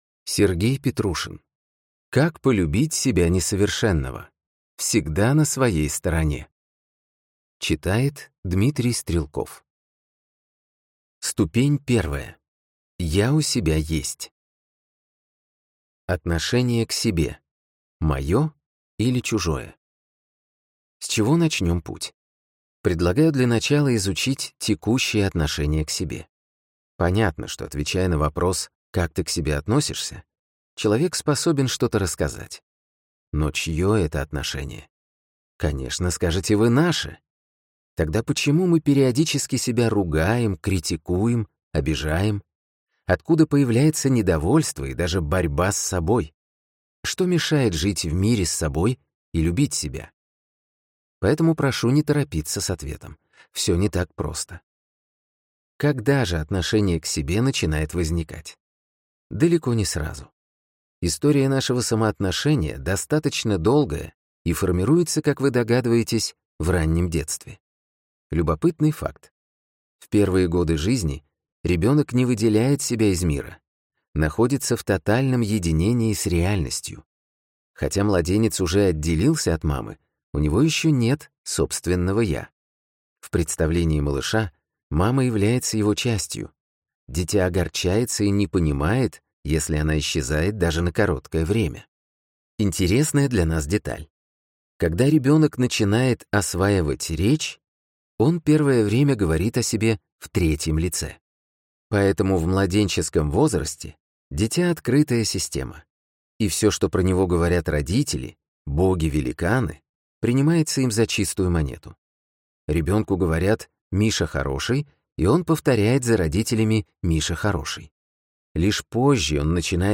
Аудиокнига Как полюбить себя несовершенного. Всегда на своей стороне!